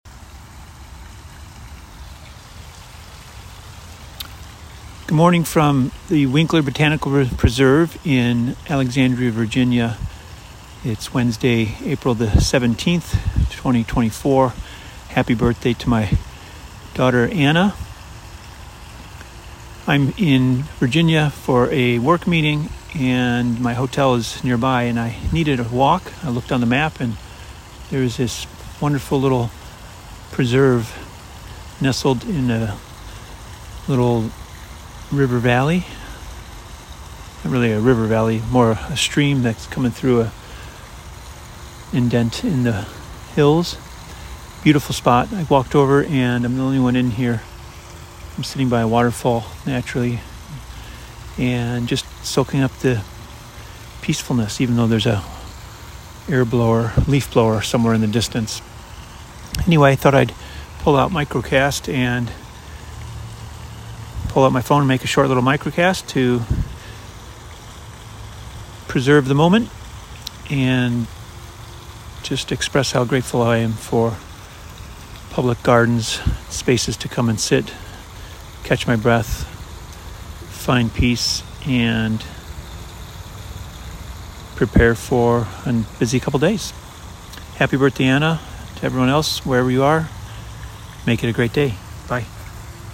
Waterfall in the preserve
I needed a walk and saw the Winkler Botanical Preserve nearby.